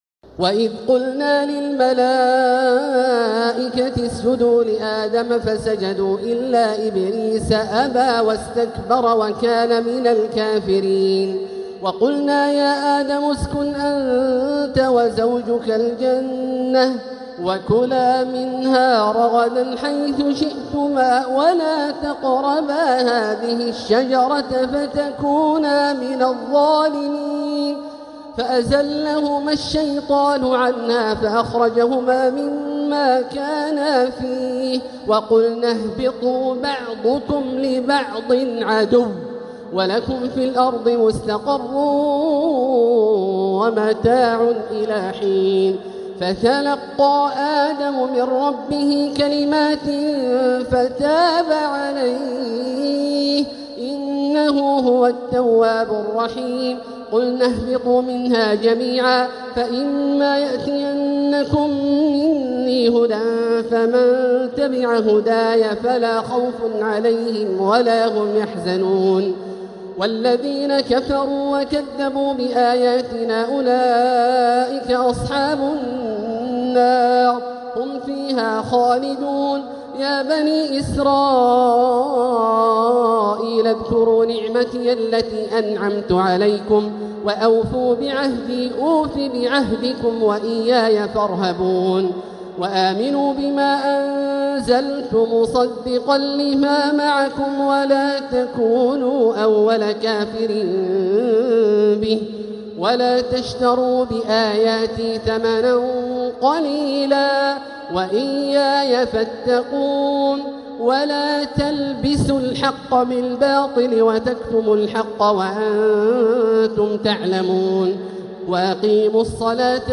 مقتطفات مرئية من محراب الحرم المكي من ليالي التراويح للشيخ عبدالله الجهني 1446هـ > تراويح الحرم المكي عام 1446 🕋 > التراويح - تلاوات الحرمين